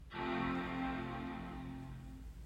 macbook on.m4a
Category 🗣 Voices
computer MacBook start-up sound effect free sound royalty free Voices